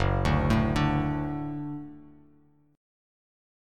F#m#5 chord